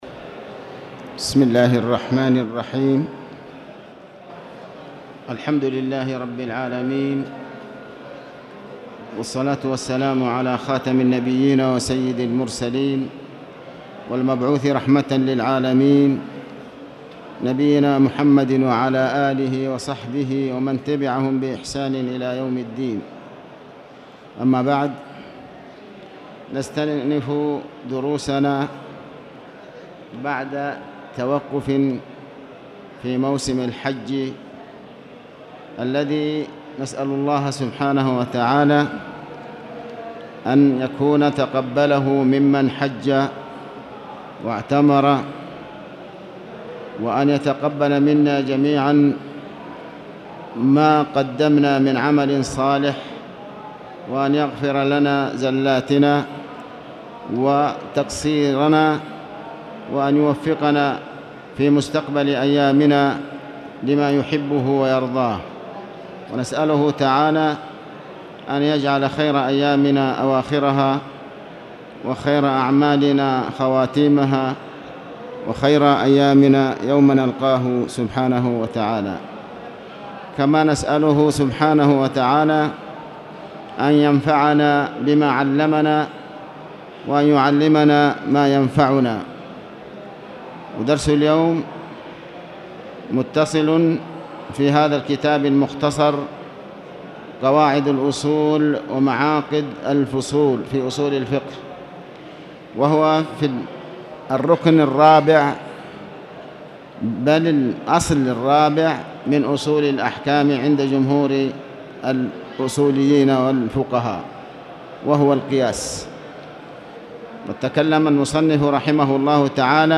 تاريخ النشر ٢٦ ذو الحجة ١٤٣٧ هـ المكان: المسجد الحرام الشيخ: علي بن عباس الحكمي علي بن عباس الحكمي القياس